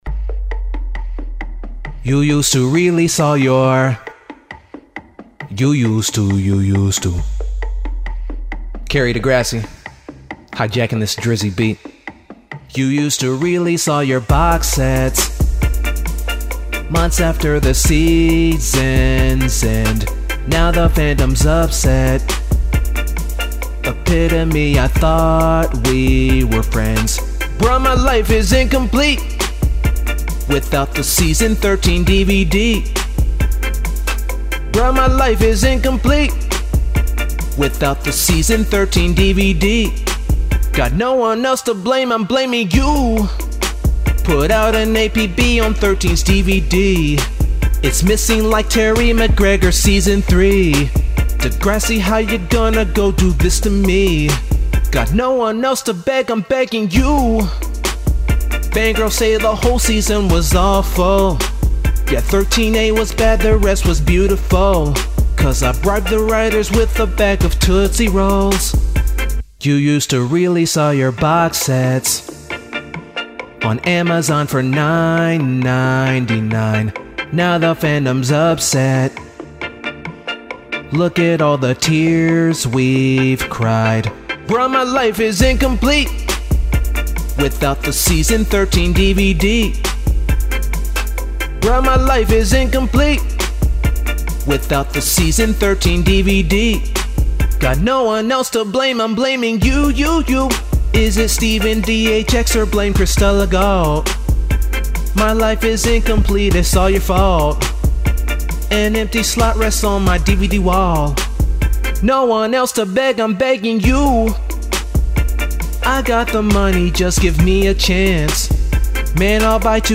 parody song